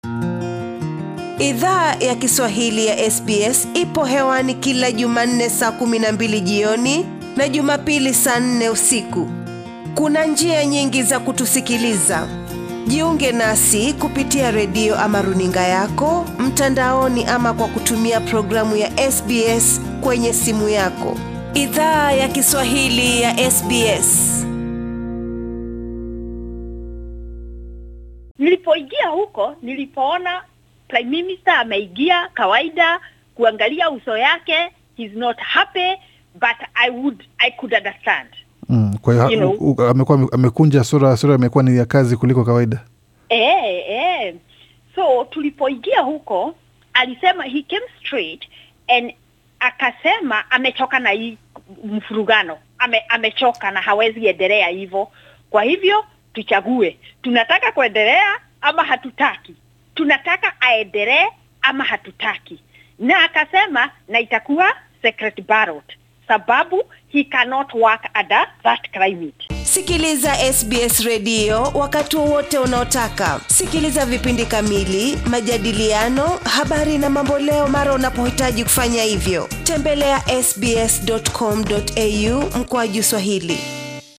Hiki hapa kionjo cha mahojiano tuliyo fanya naye, ambapo alifunguka kuhusu yaliyo jiri wiki hii ndani ya chama tawala cha Liberal.